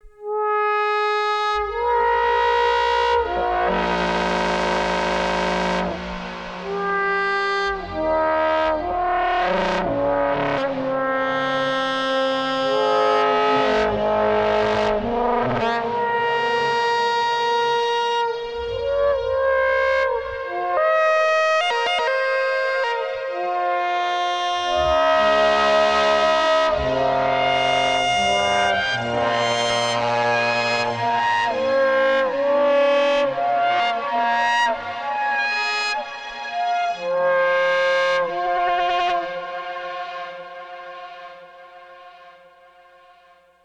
stumbled upon this broken trumpet sound today with some osc sync + fm and a few mod slots